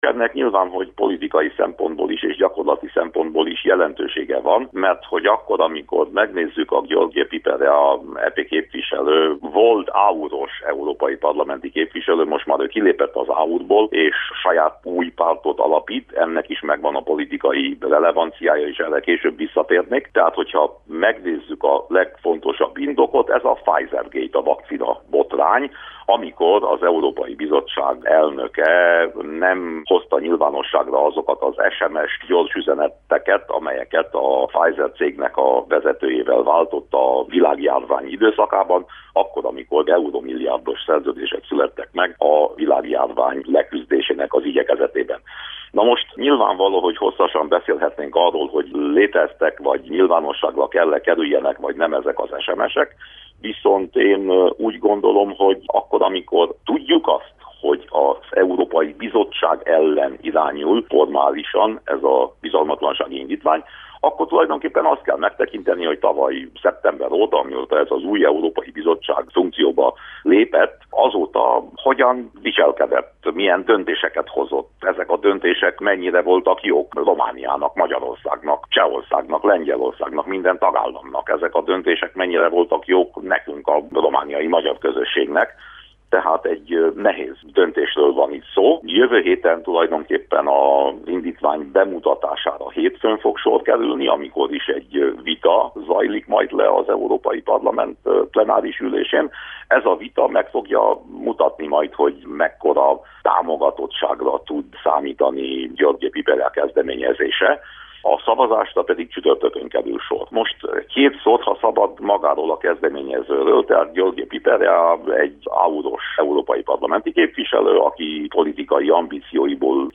Hasónlóan vélekedik Winkler Gyula EP képviselő is, aki a Kolozsvári Rádiónak nyilatkozva azt is megjegyezte, hogy nehéz vita vár az Európai Parlamentre.